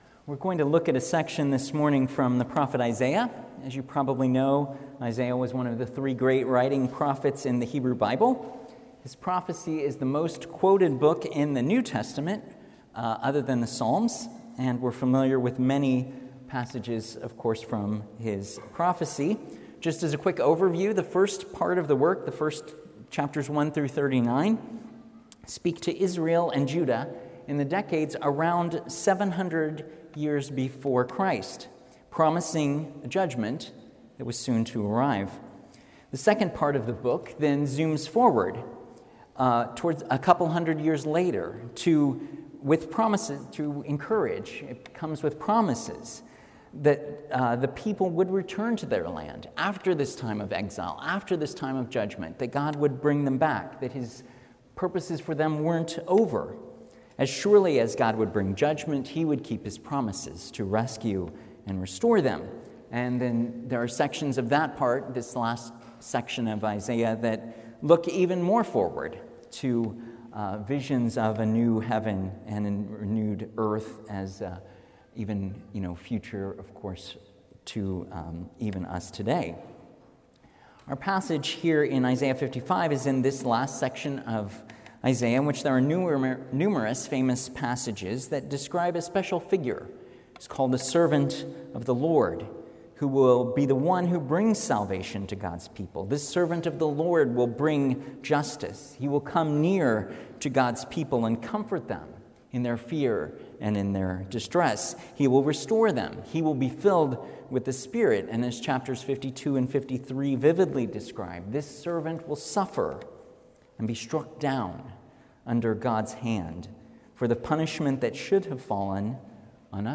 A message from the series "Ruth."
From Series: "Standalone Sermons"